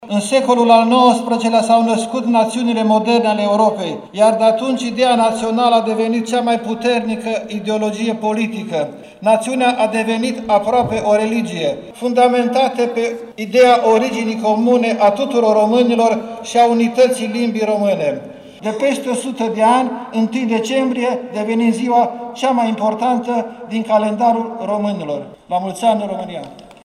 Ziua Națională, sărbătorită la Tg. Mureș
Programul, mai scurt ca de obicei, a debutat cu o ceremonie militară și religioasă, urmată de un discurs privind semnificația zilei